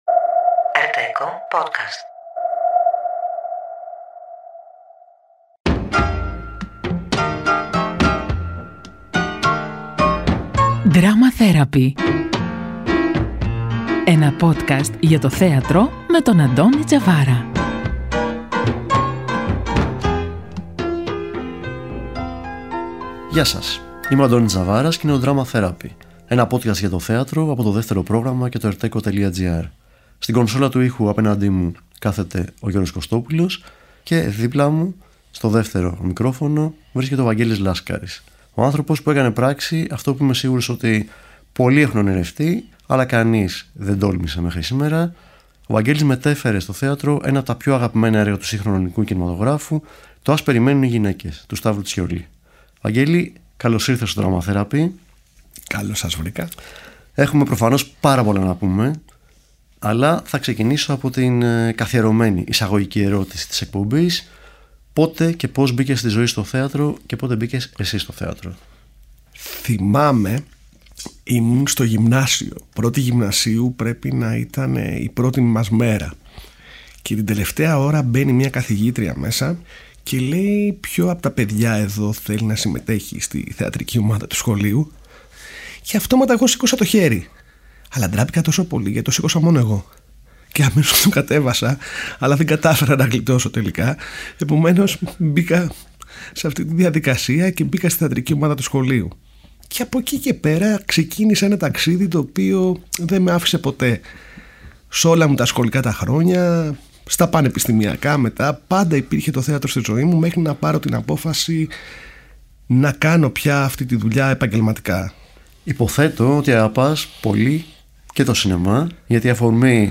Ένα podcast για το θέατρο από το Δεύτερο Πρόγραμμα και το ERTecho Στο Drama Therapy άνθρωποι του θεάτρου, κριτικοί και θεατές συζητούν για τις παραστάσεις της σεζόν αλλά και για οτιδήποτε μπορεί να έχει ως αφετηρία ή ως προορισμό τη θεατρική πράξη.